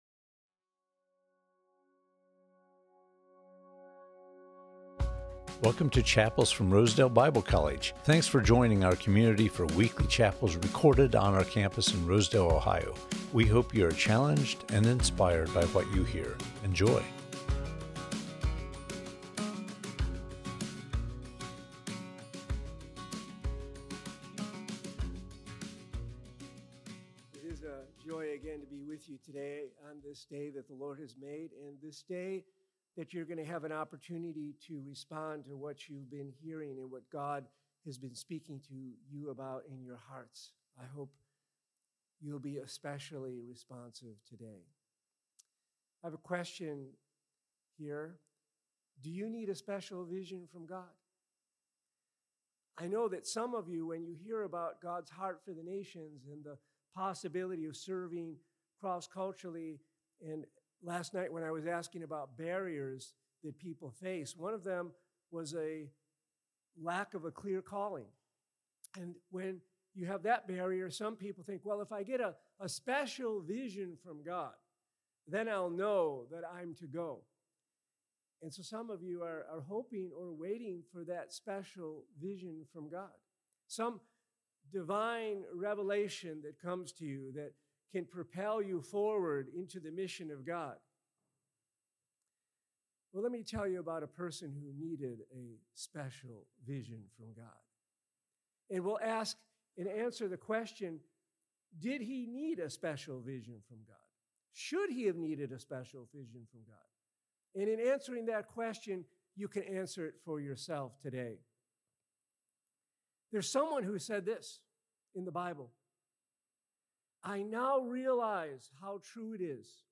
Chapels from Rosedale Bible College Do You Need a Special Vision from God?